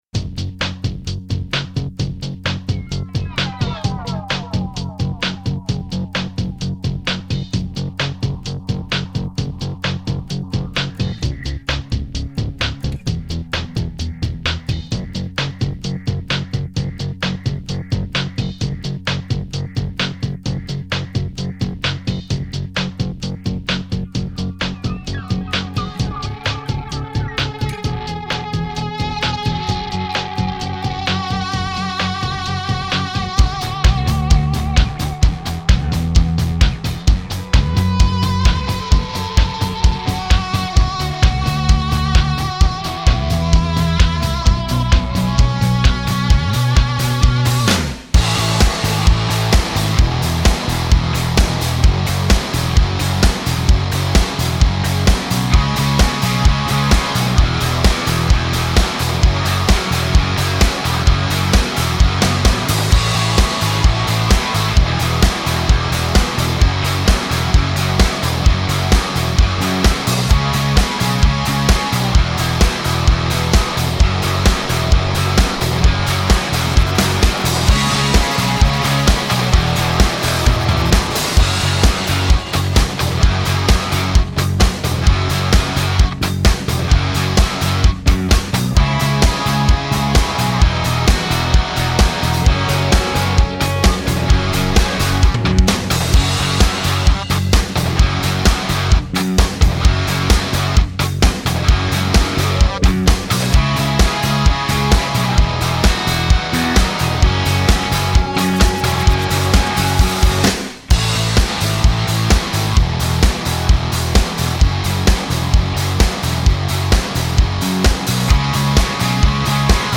Key F#/Gb
Instrumental Track